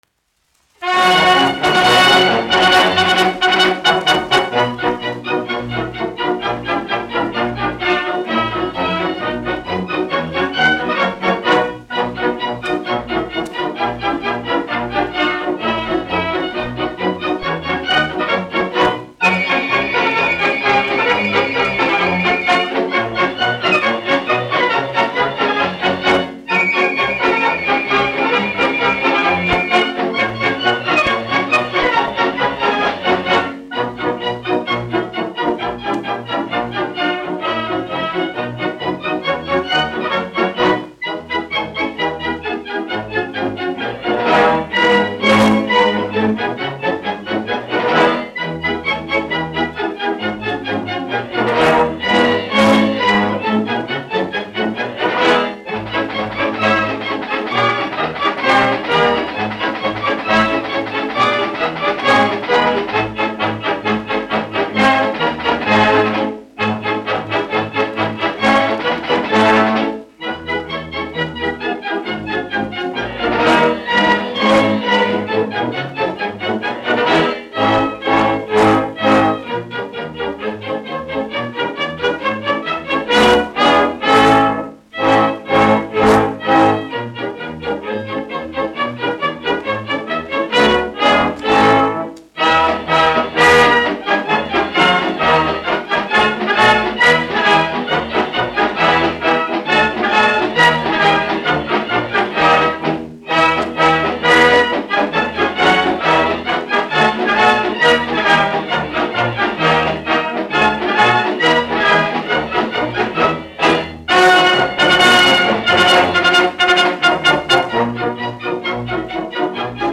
Jānis Mediņš, 1890-1966, diriģents
1 skpl. : analogs, 78 apgr/min, mono ; 25 cm
Tautasdziesmas, latviešu--Instrumentāli pārlikumi
Polkas
Latvijas vēsturiskie šellaka skaņuplašu ieraksti (Kolekcija)